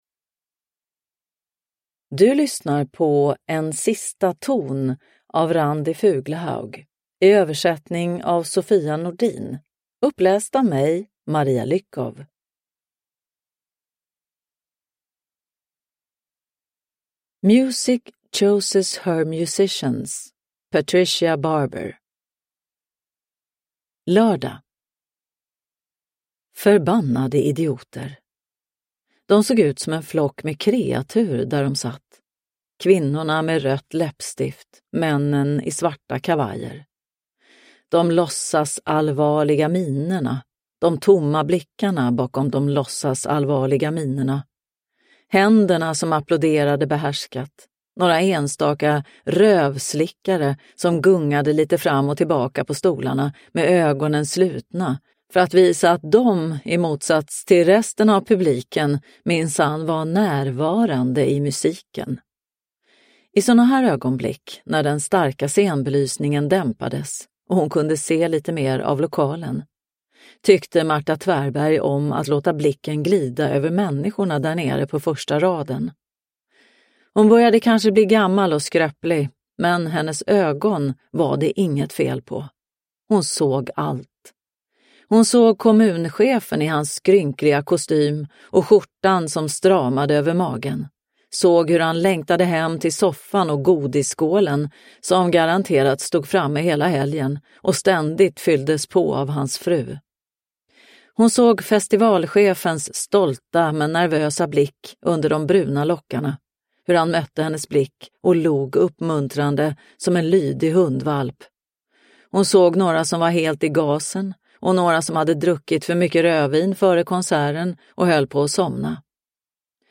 En sista ton – Ljudbok – Laddas ner